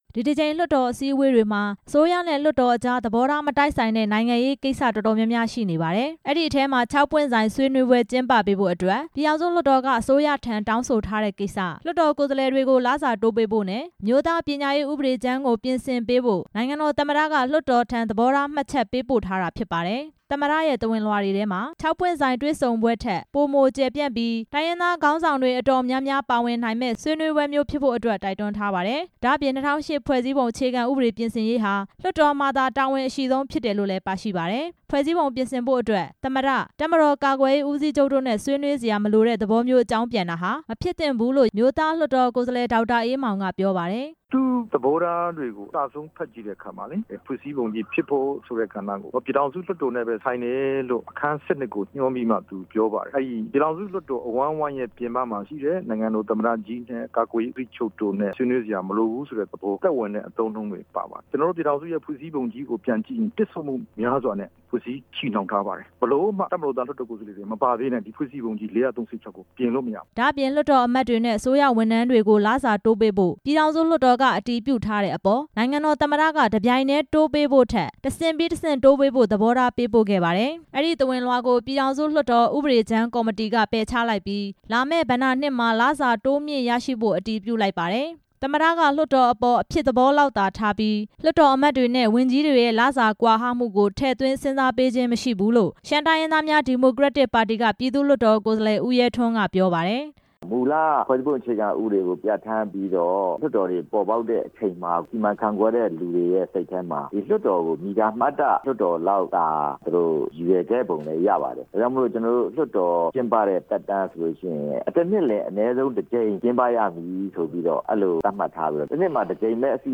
သမ္မတက လွှတ်တော်အပေါ် အဖြစ်သဘောလောက်သာထားပြီး လွှတ်တော်အမတ်တွေနဲ့ ဝန်ကြီးတွေရဲ့ လစာကွာဟမှုကို ထည့်သွင်းစဉ်းစားပေးခြင်းမရှိဘူးလို့ ပြည်သူ့လွှတ်တော် ကိုယ်စားလှယ် ဦးရဲထွန်းက ပြေပါတယ်။